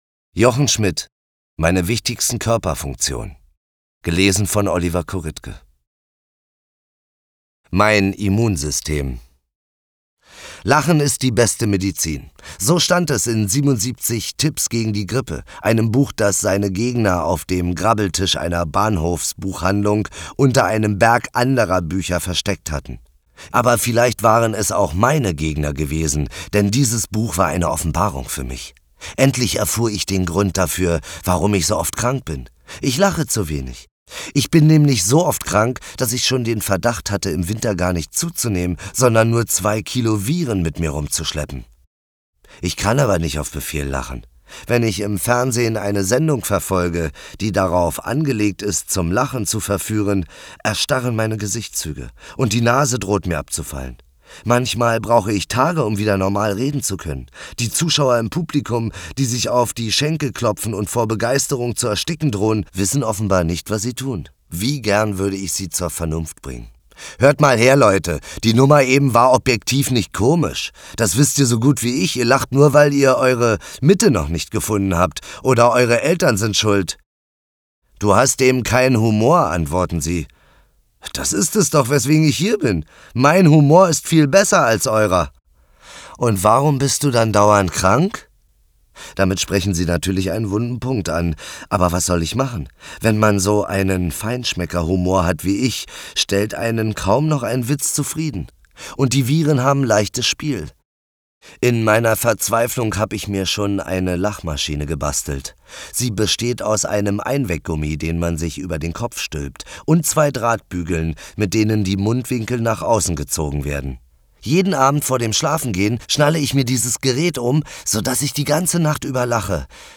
Ungekürzte Lesung
Oliver Korittke (Sprecher)